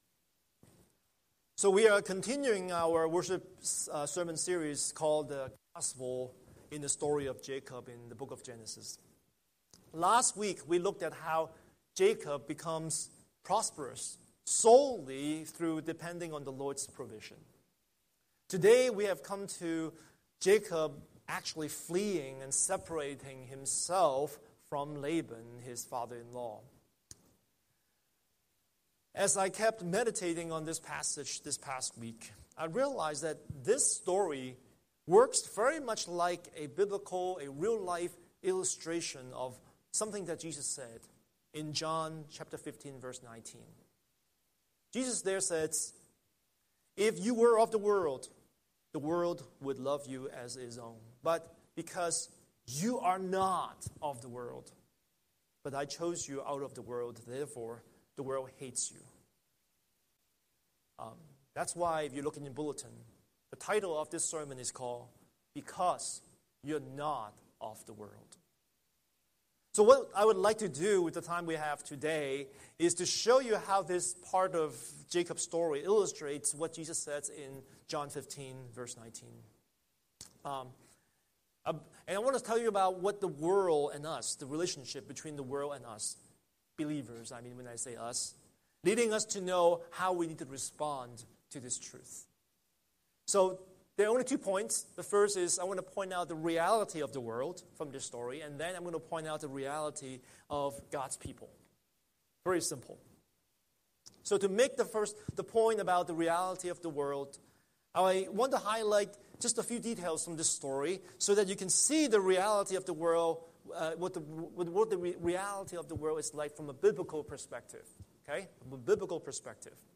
Scripture: Genesis 31:17–55 Series: Sunday Sermon